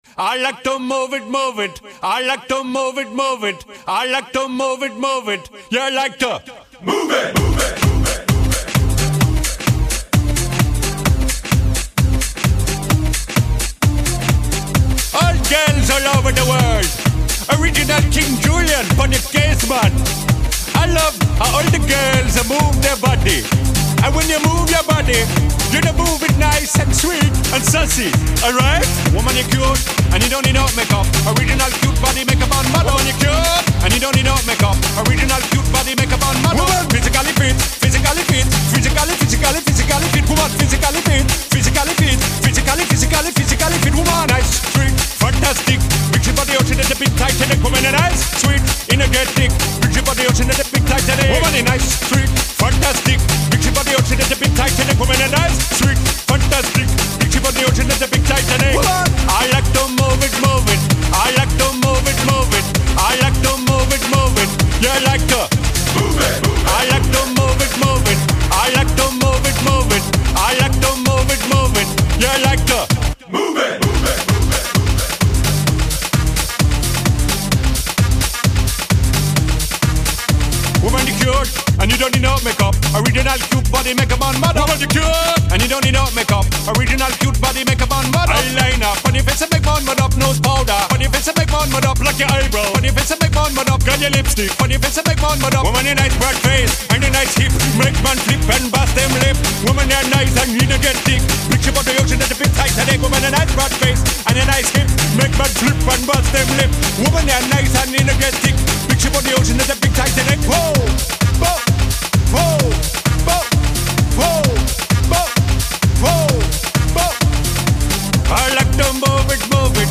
原声音乐 Soundtrack
动画片配乐 Animation、家庭片配乐 Family、冒险片配乐 Adventure、喜剧片配乐 Comedy